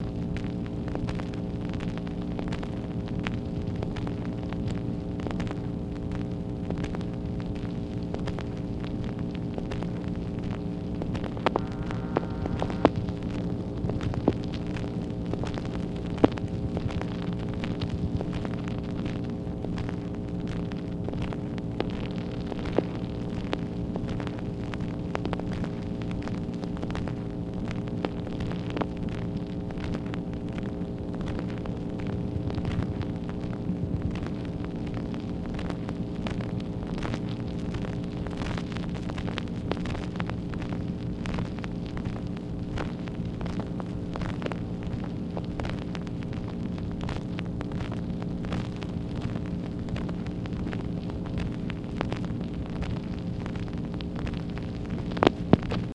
MACHINE NOISE, August 21, 1964
Secret White House Tapes | Lyndon B. Johnson Presidency